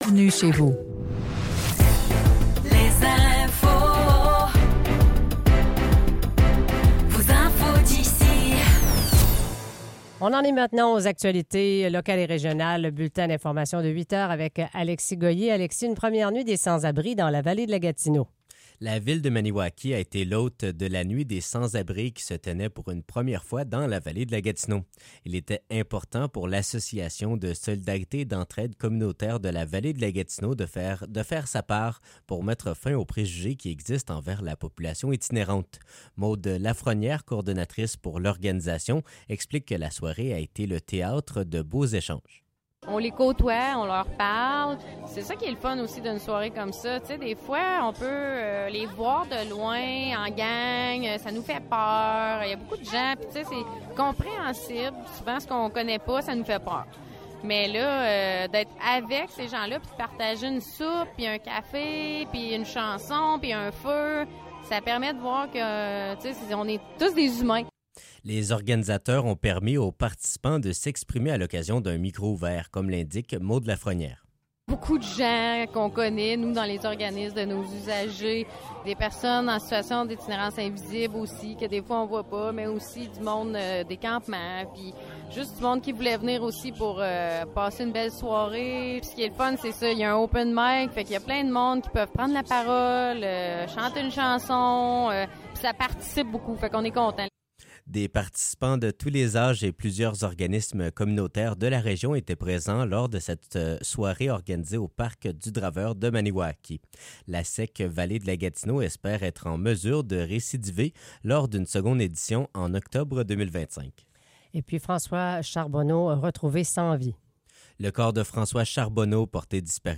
Nouvelles locales - 21 octobre 2024 - 8 h